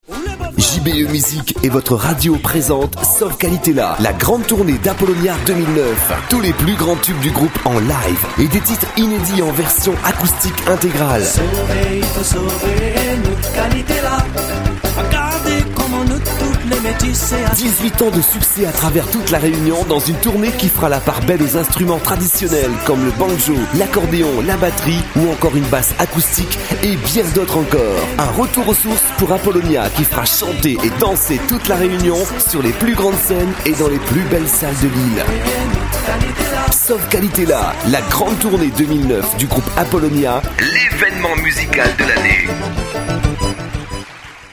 Les Spots Pub Radio
Le spot d'annonce de la Tournée